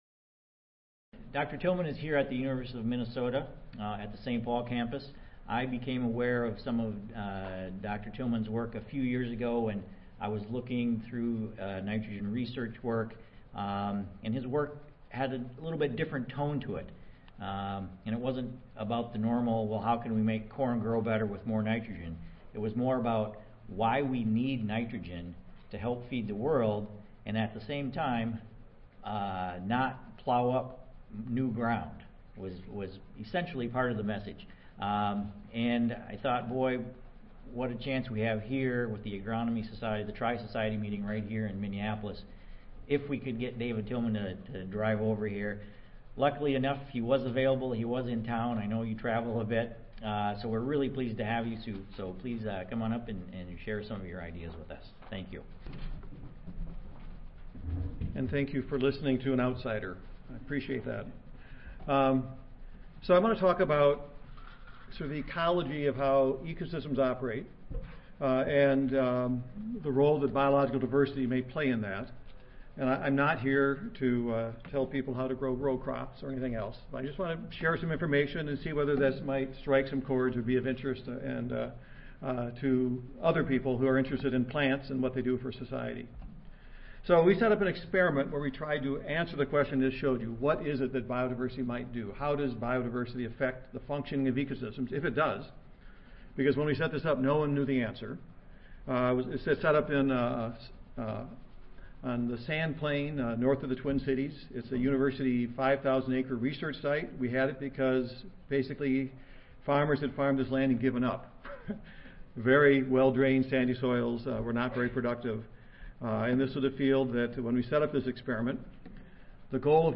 See more from this Division: C02 Crop Physiology and Metabolism See more from this Session: Symposium--Efficient Resource Utilization for Improving Crop Productivity and Environmental Stewardship
David Tilman , Department of Ecology, Evolution and Behavior, University of Minnesota, St. Paul, MN Audio File Recorded Presentation Abstract: Changes in plant biodiversity can have greater impacts on the productivity of prairie grassland ecosystems than nitrogen fertilization, irrigation, elevated CO2, and fire.